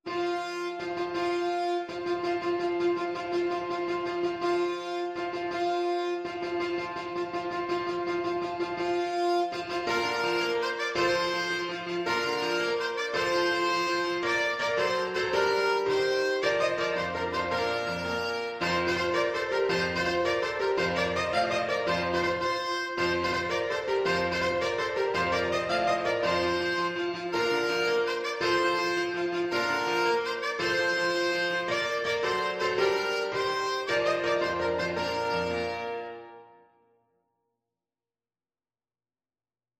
Classical Grétry, André La Victoire est a Nous (French Imperial March) Alto Saxophone version
Alto Saxophone
With energy .=c.110
Bb major (Sounding Pitch) G major (Alto Saxophone in Eb) (View more Bb major Music for Saxophone )
6/8 (View more 6/8 Music)
F5-F6
Classical (View more Classical Saxophone Music)